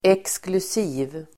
Uttal: [eksklus'i:v]